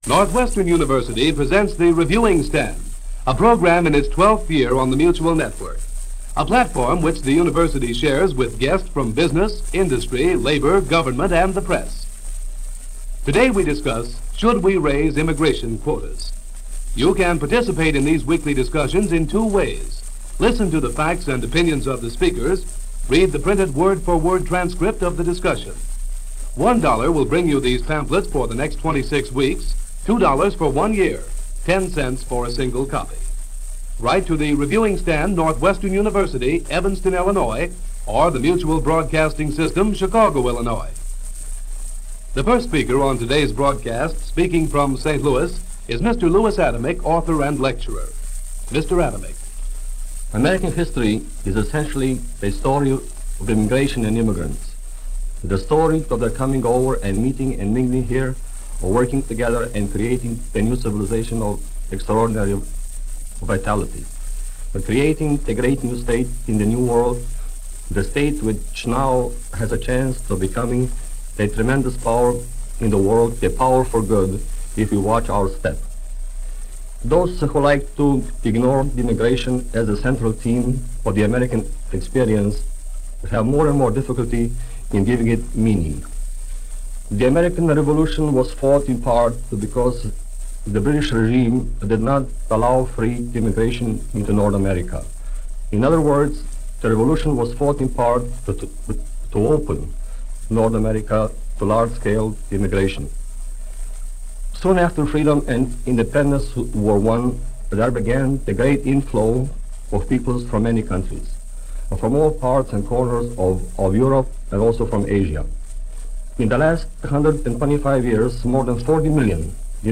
As we’re reminded in this discussion that America was built on people escaping other counties in favor of this one.